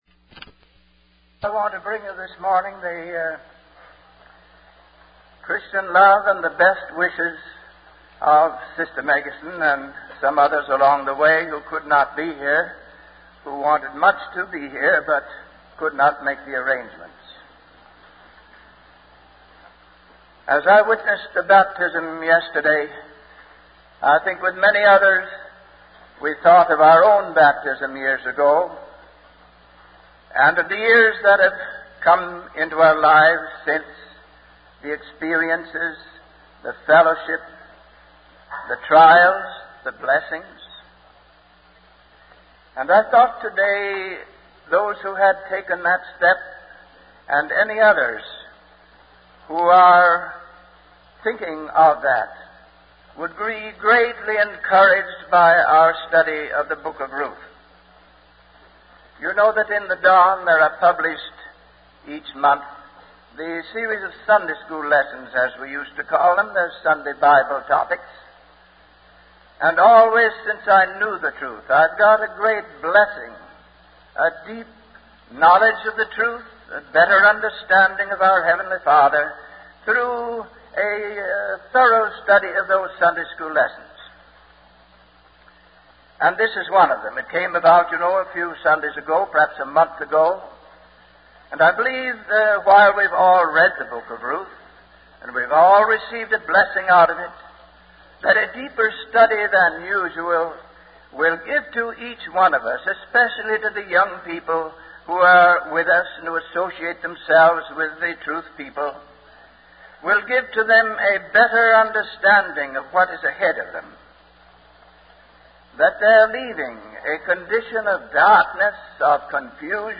From Type: "Discourse"
Bowling Green Convention Related Topics